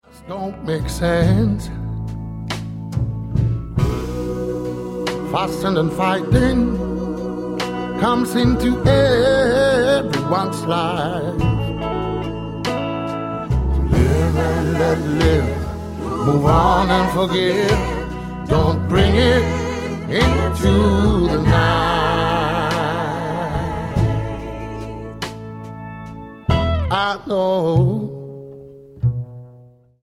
12-stringed acoustic guitar
pedal steel, lap steel
upright bass
drums, percussion
backing vocals, organ